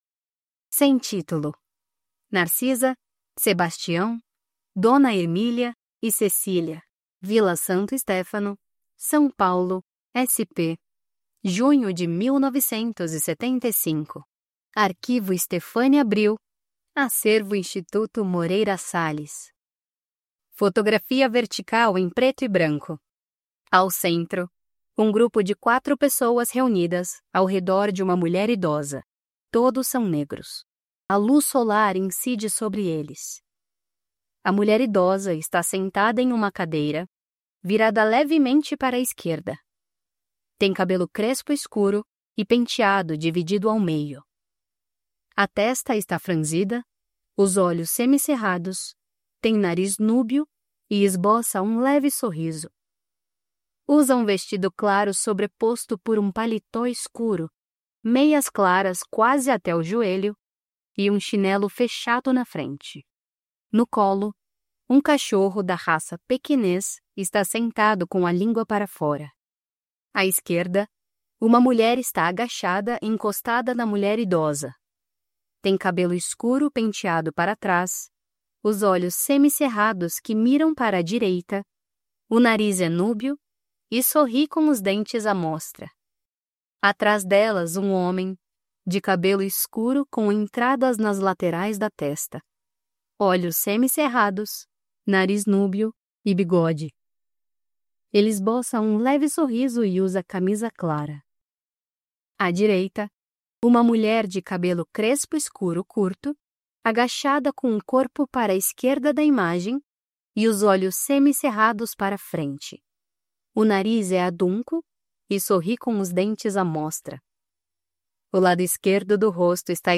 Audiodescrições